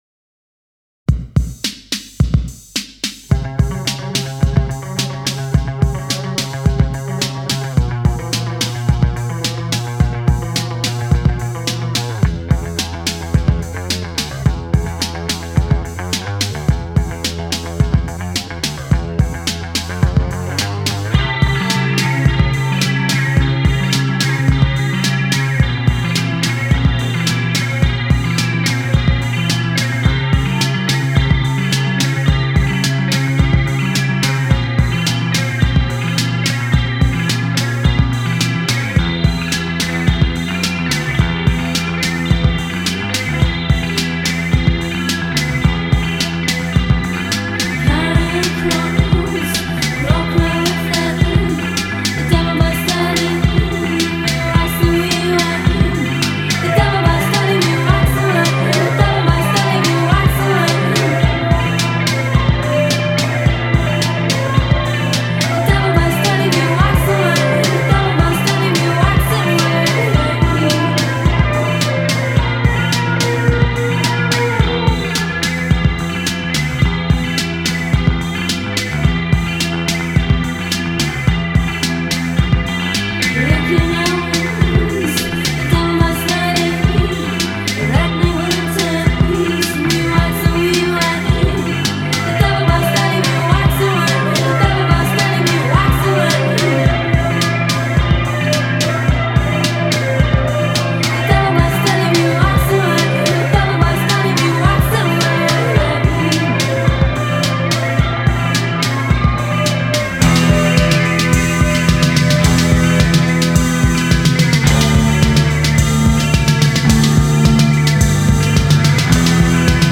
distinctive soprano wordless vocals